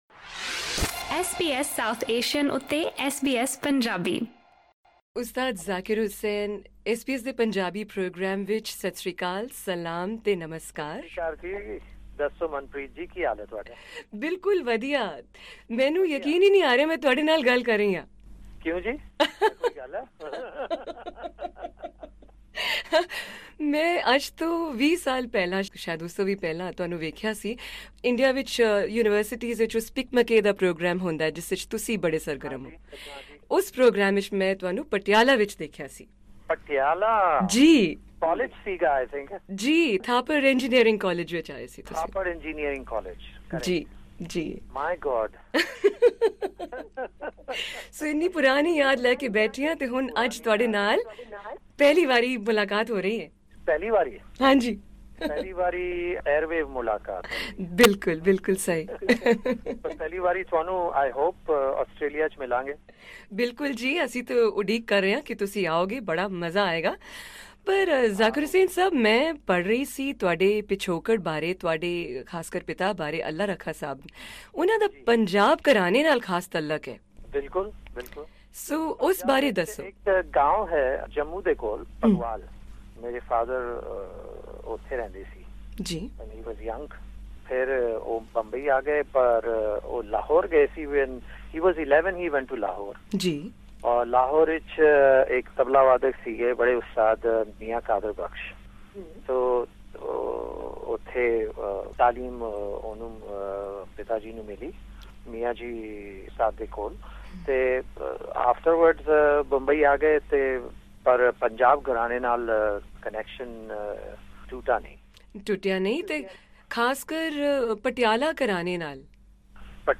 Tabla maestro Zakir Hussain's rare interview in Punjabi
SBS Punjabi recorded an insightful interview with Indian-origin, world-renowned, Grammy-winning musician, Ustad Zakir Hussain in 2011, in which he talked about his lesser known Punjabi heritage and his family's musical connection with Punjab.